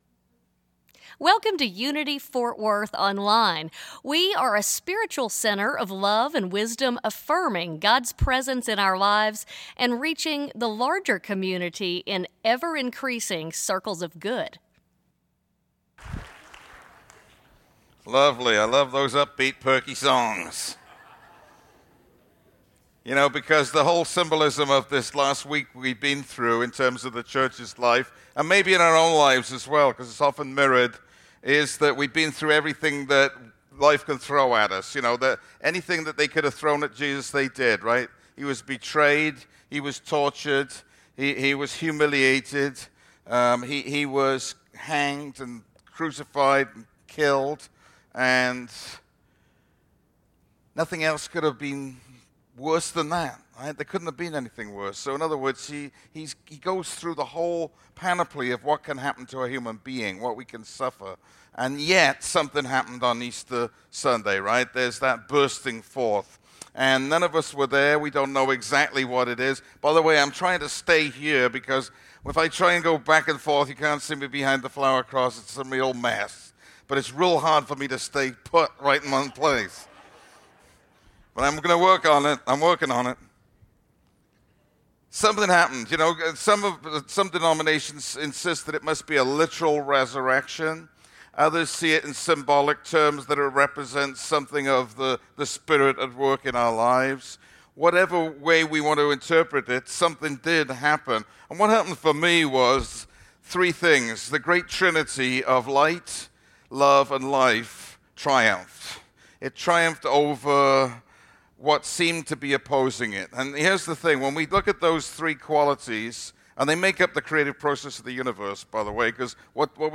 In this Easter message we explore the Truth of the three great qualities that have no opposites: Light, Love, and Life. The realization of his inherent Oneness in Light, Love, and Life resulted in Jesus’ resurrection.